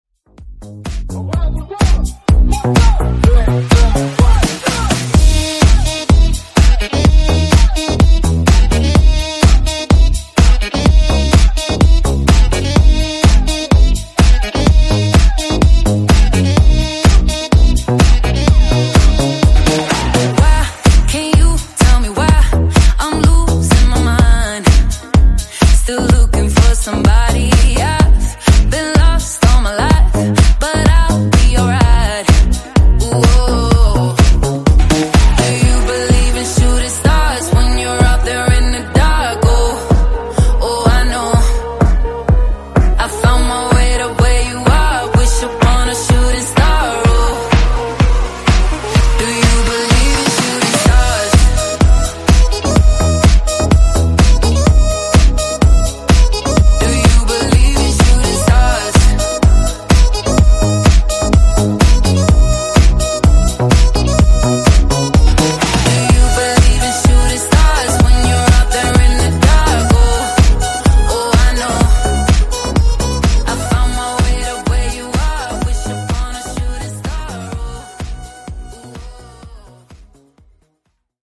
Genre: RE-DRUM Version: Clean BPM: 115 Time